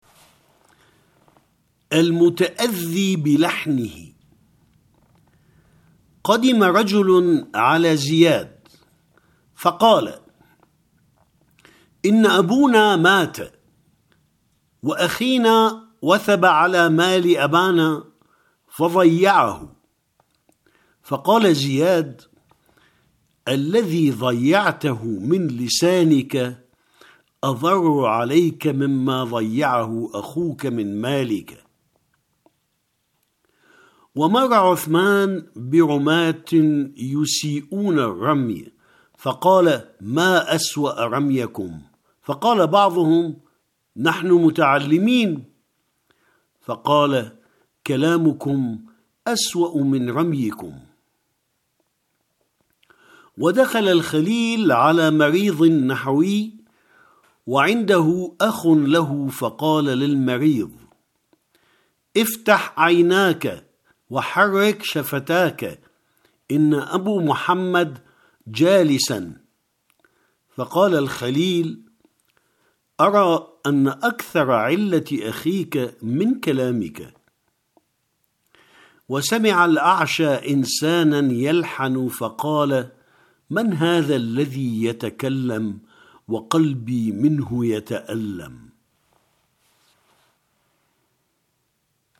- Il se peut que des erreurs de prononciation se présentent dans les documenst sonores ; une version corrigée sera mise en ligne par la suite.